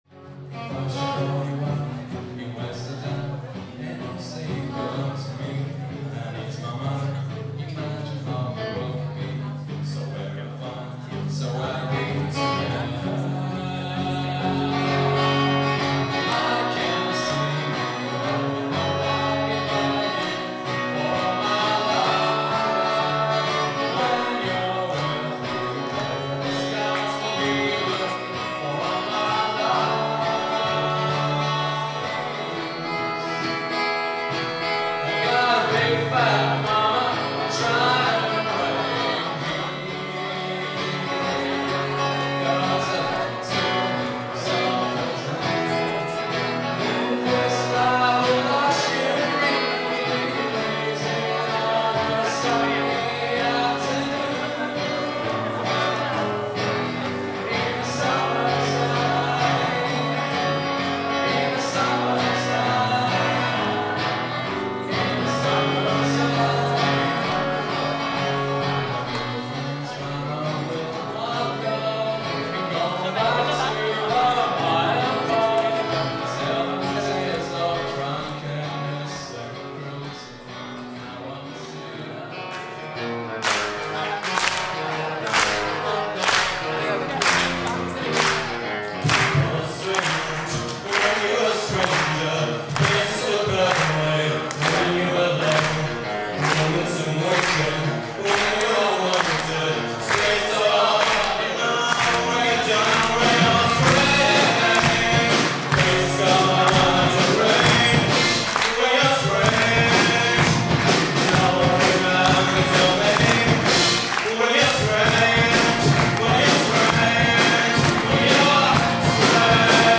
Rockkonzert an unserer Schule
Am Abend des 14.4.2011 gab es im Theatersaal von St. Georg ein Rockkonzert, bei dem Lehrer und Schüler gemeinsam mitwirkten.